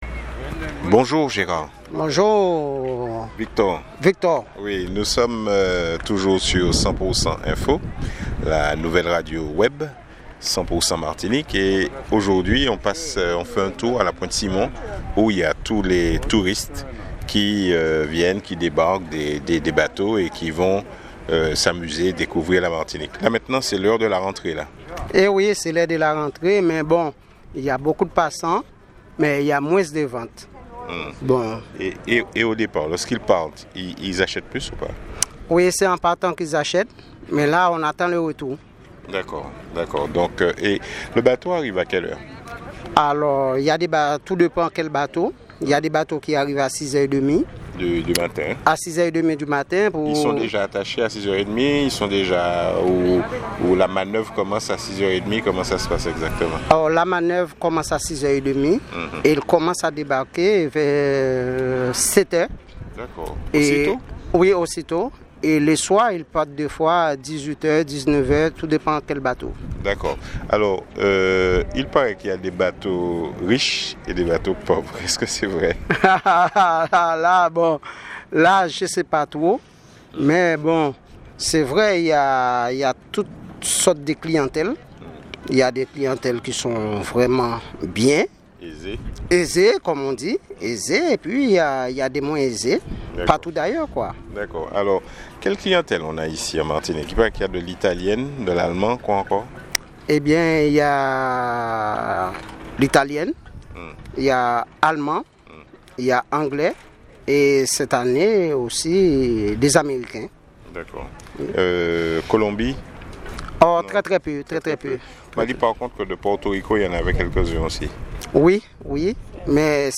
Nous avons réalisé un reportage sur le terminal de la Pointe Simon et les commerçants nous annonçaient la fin de la saison des croisières.